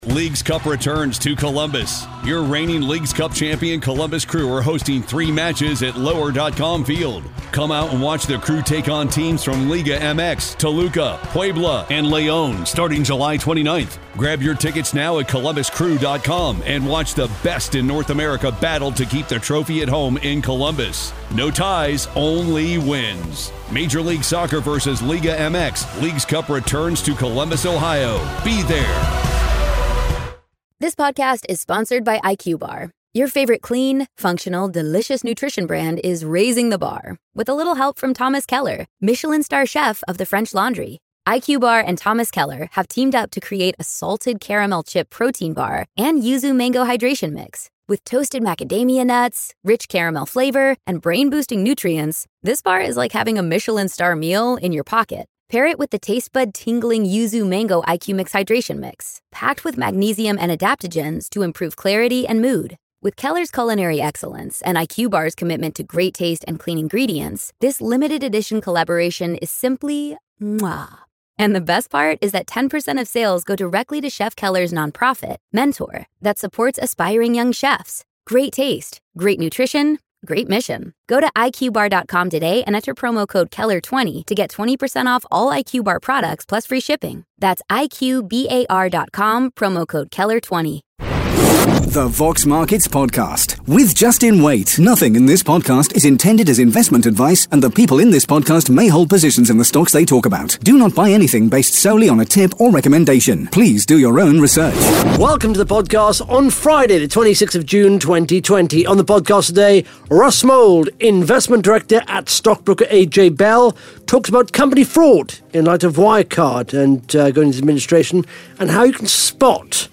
(Interview starts at 18 minutes 37 seconds)